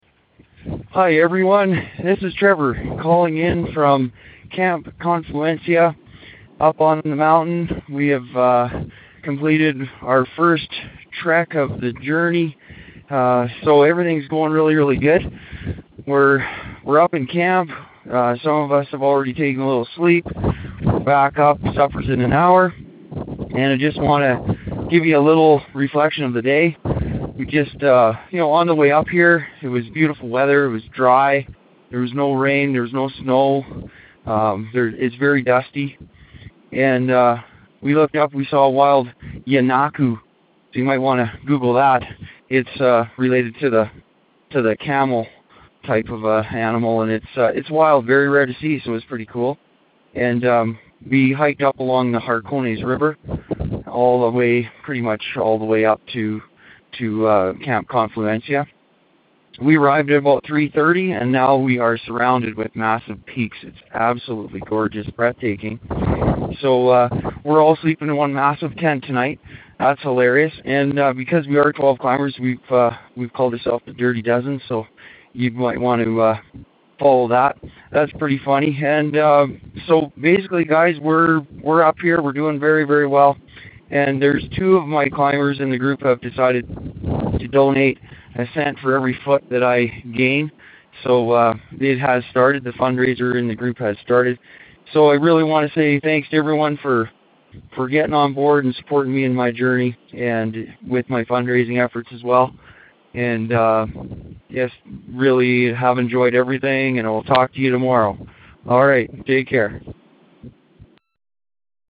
Aconcagua Expedition Dispatch